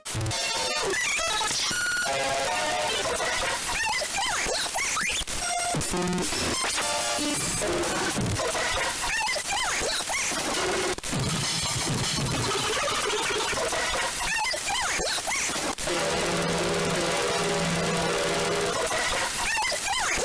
Προσοχή: Τα αρχεία αυτού του μπλόγκ περιέχει δυνατό ήχο σε ορισμένα σημεία και κάποια τρομακτικά στοιχεία.
Αυτό έχει τροποποιηθεί ανάλογα με την ένταση του ήχου για να μην καταστραφούν τα ηχεία σας!